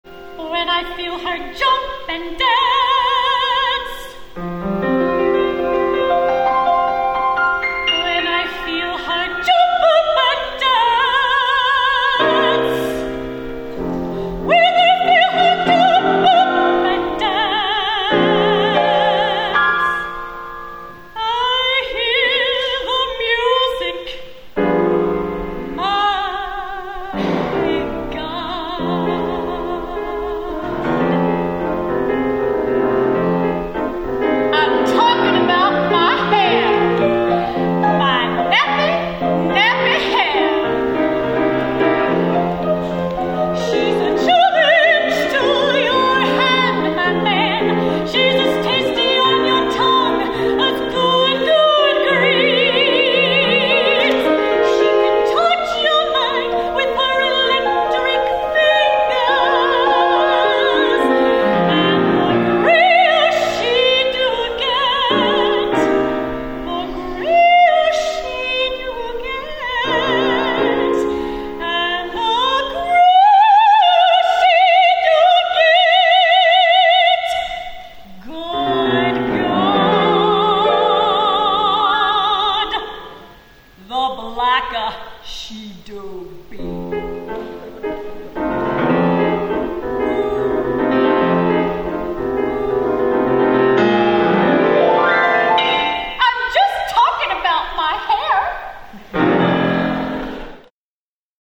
for Soprano and Piano (1997)